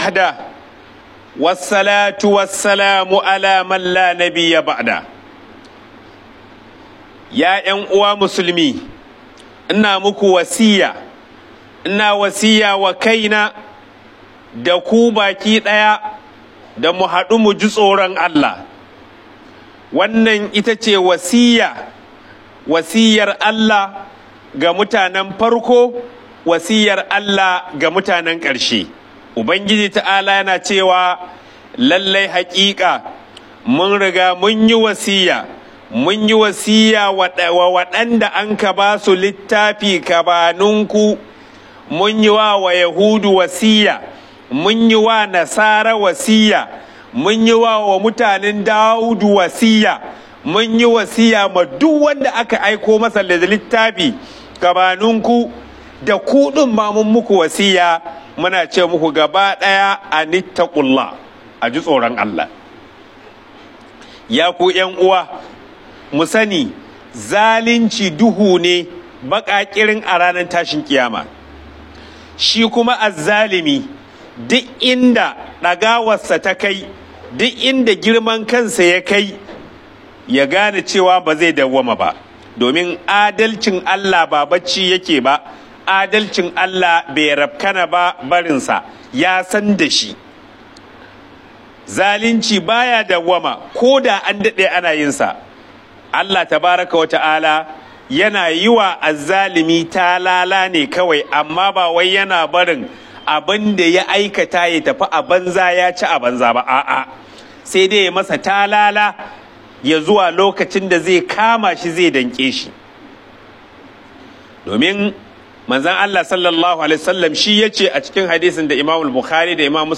Huɗubar Juma'a Daga Sabon Kaura